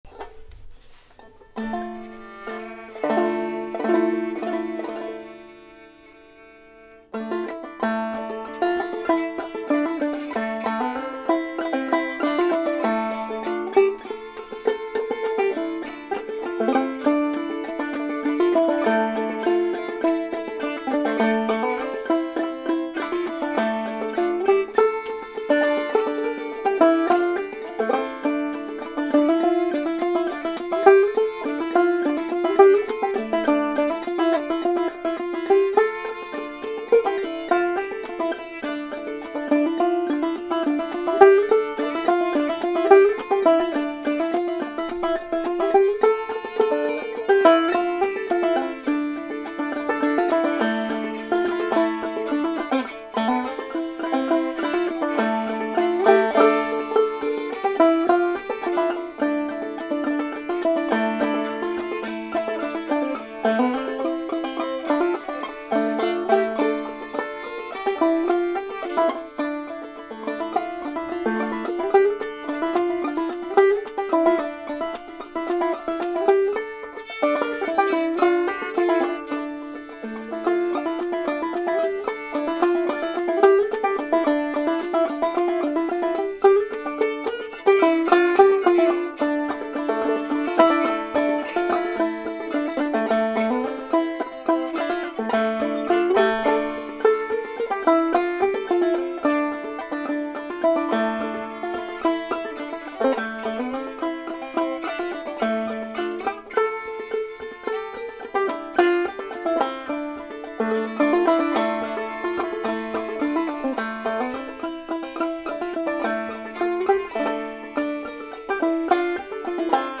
Sally In The Garden, traditional fiddle tune performed on banjo and fiddle